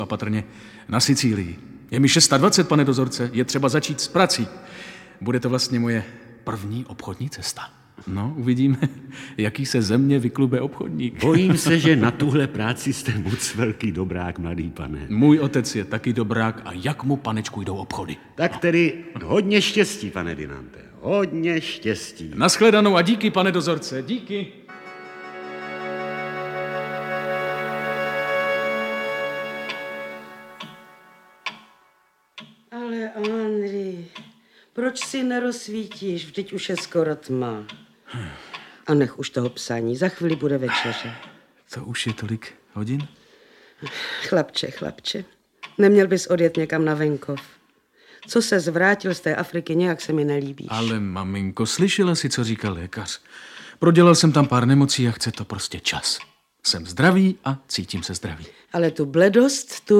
Audiobook
Audiobooks » Short Stories
Read: Otakar Brousek